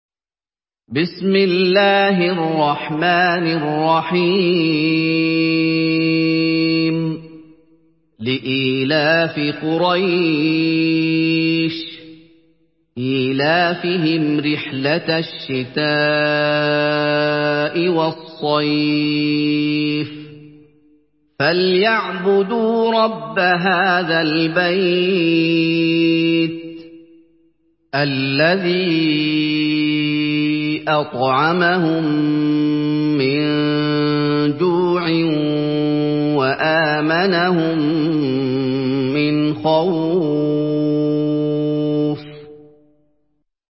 Surah ক্বুরাইশ MP3 by Muhammad Ayoub in Hafs An Asim narration.
Murattal Hafs An Asim